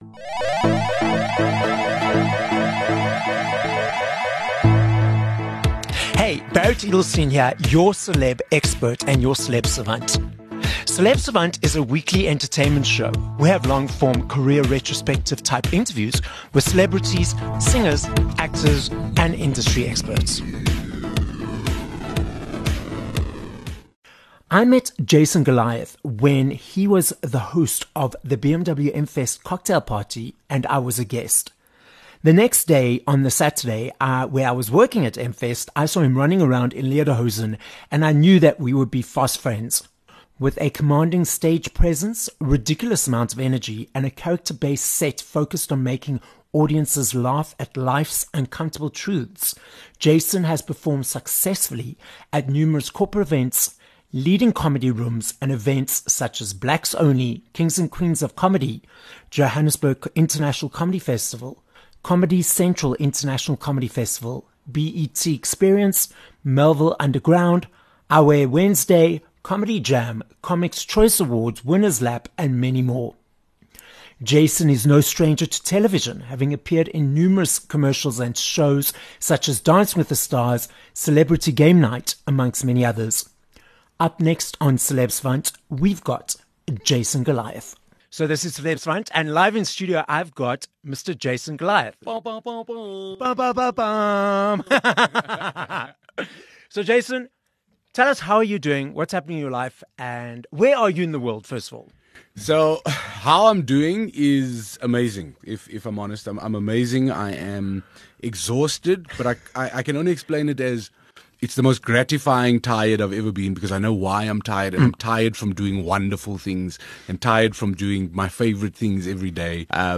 10 Jan Interview with Jason Goliath